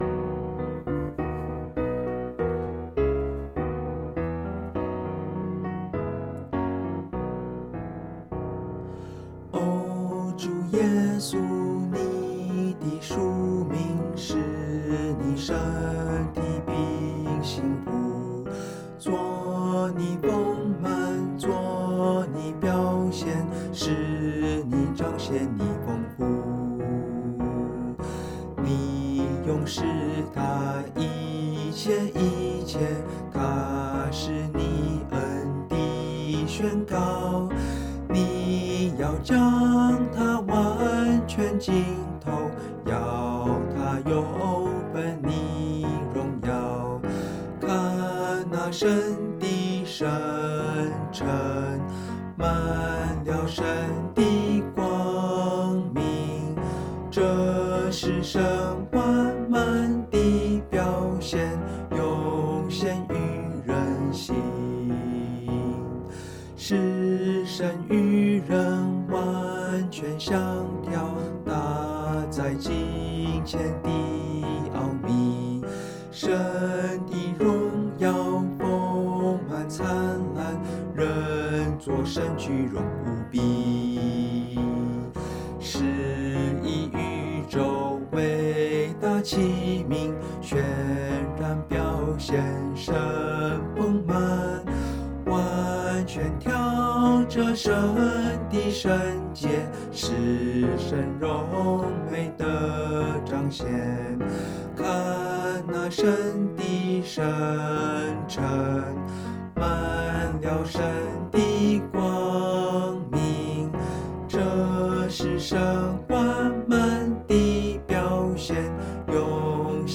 降E大調